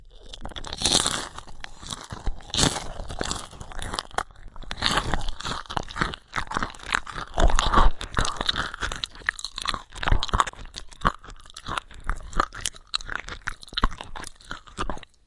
恐怖片 " 吃僵尸
标签： 僵尸 邪恶 恐怖 怪物 亡灵
声道立体声